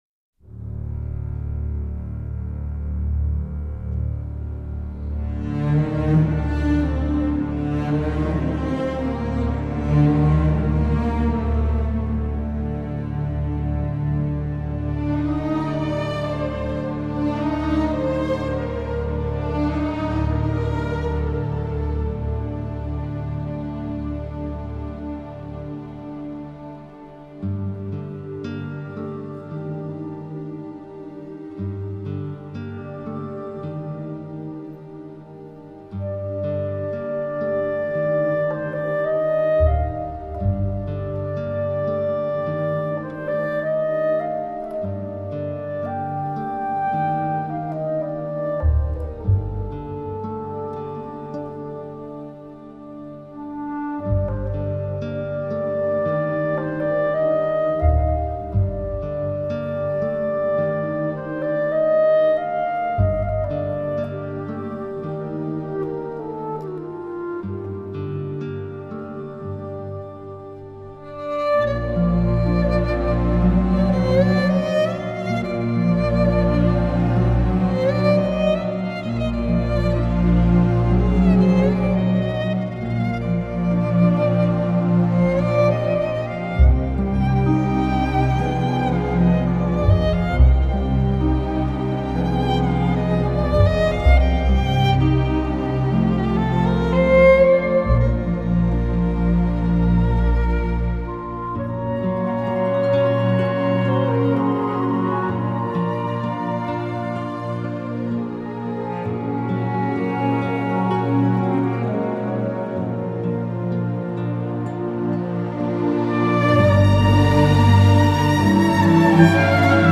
低沉耐人寻味专辑延续了小提琴和钢琴在乐曲中的主导地位和对古朴叙事歌谣的表现力
融合了爱尔兰空灵飘渺的乐风，挪威民族音乐及古典音乐
乐曲恬静深远，自然流畅。